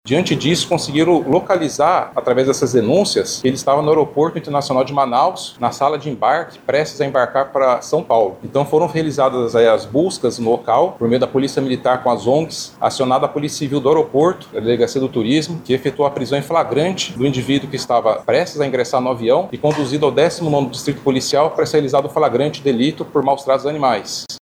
Ainda segundo o delegado, a localização do homem também foi informada à Polícia por meio de denúncias.